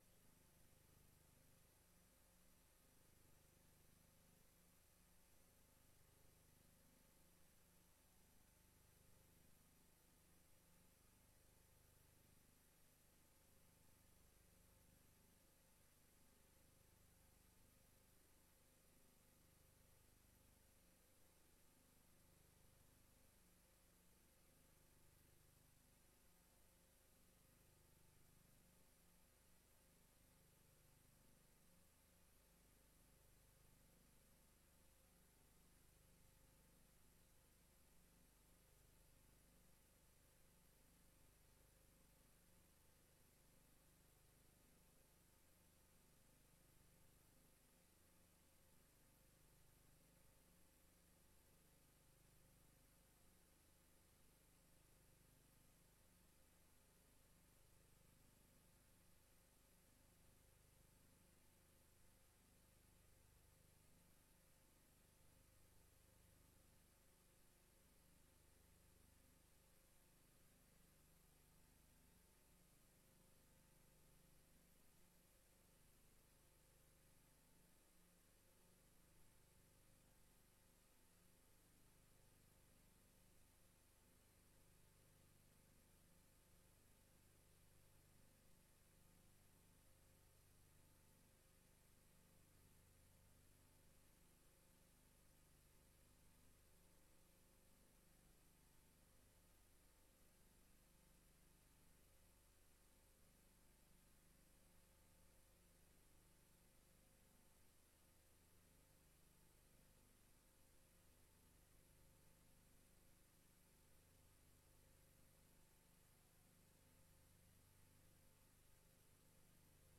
Raadsvergadering 30 maart 2022 19:30:00, Gemeente Huizen
Locatie: Raadzaal